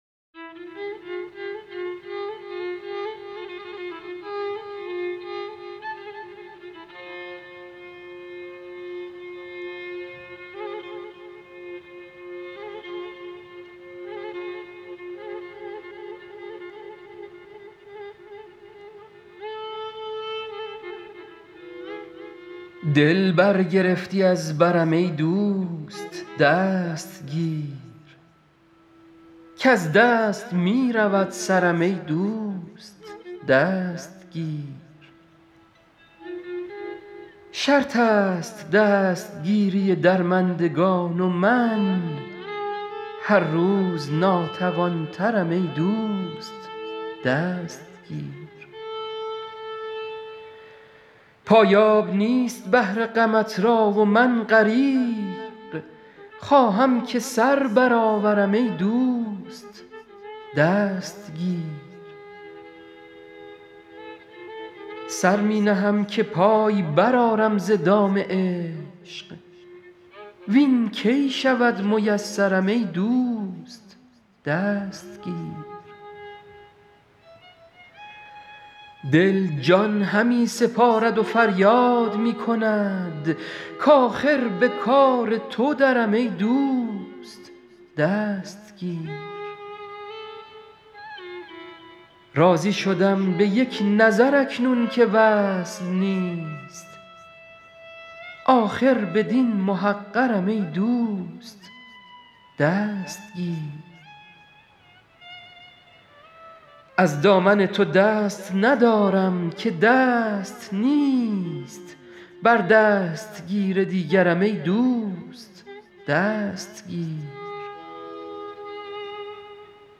غزل شمارهٔ ۳۰۷ به خوانش